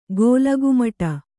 ♪ gōlagumaṭa